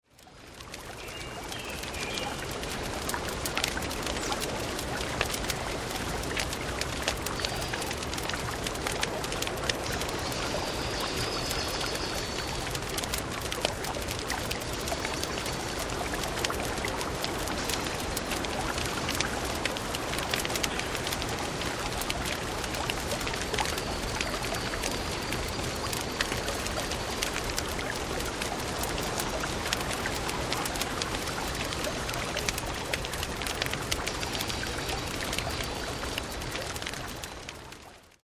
6 Stunden Lagerfeuer in der Natur (MP3)
Bei Geräuschaufnahmen sind diese ebenfalls in 44.1 kHz Stereo aufgenommen, allerdings etwas leister auf -23 LUFS gemastert.
44.1 kHz / Stereo Sound
Hoerprobe-Wald.mp3